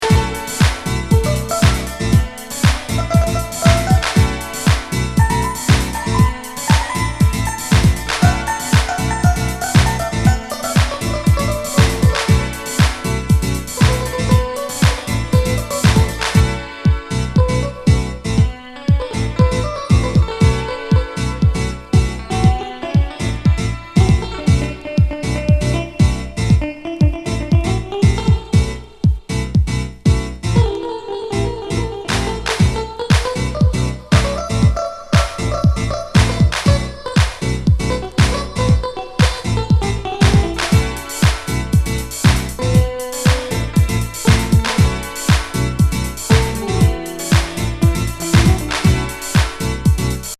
シンセギターが煌くNYディープハウス